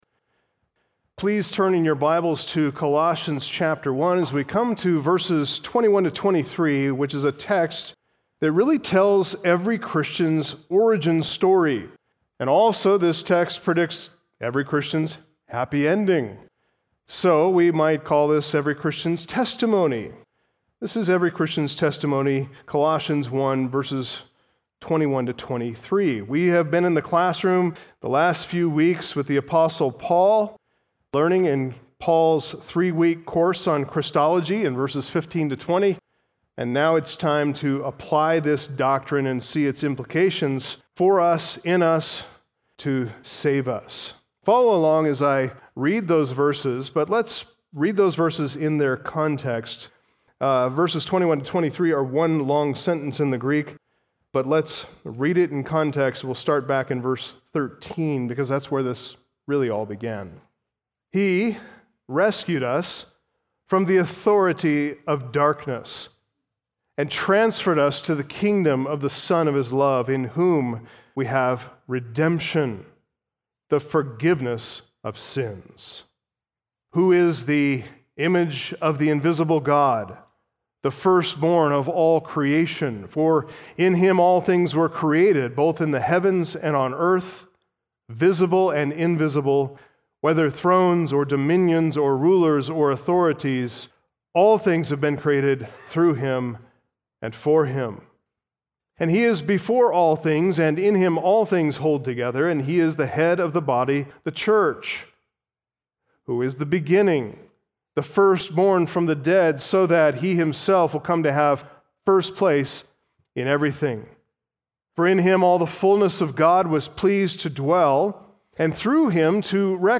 Sermons , Sunday Morning